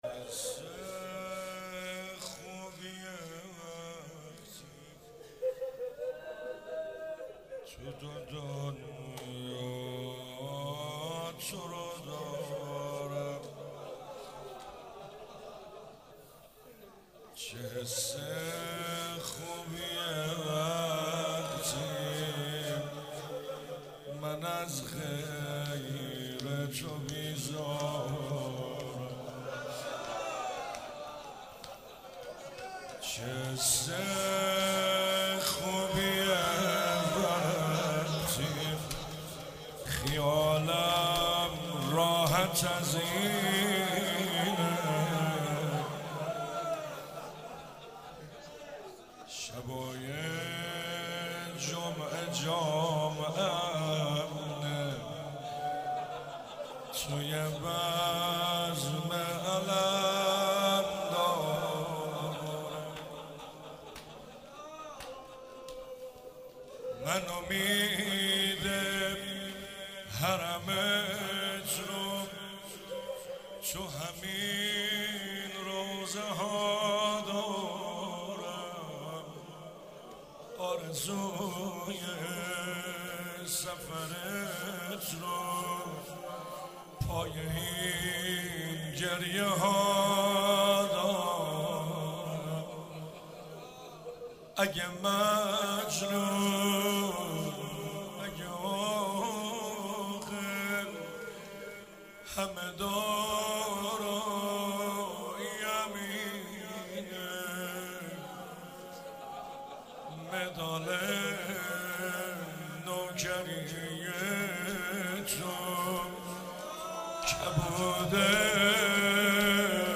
چهاراه شهید شیرودی حسینیه حضرت زینب (سلام الله علیها)